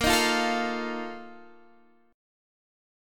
BbM7sus4 chord